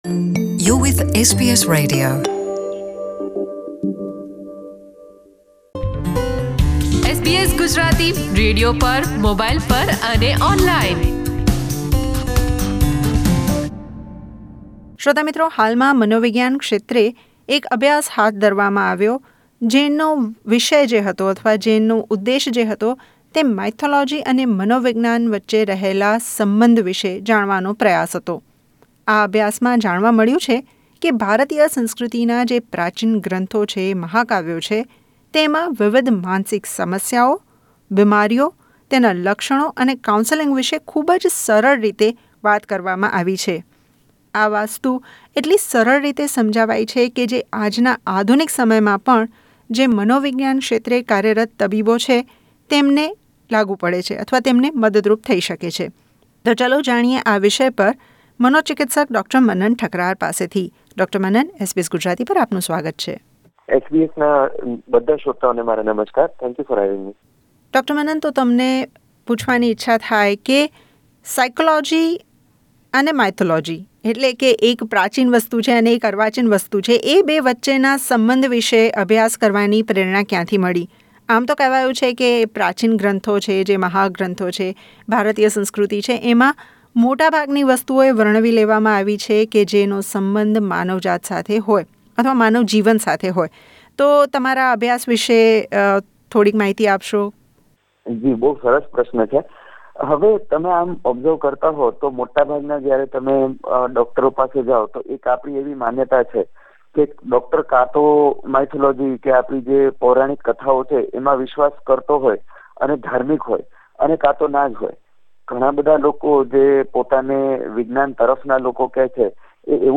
વાતચીત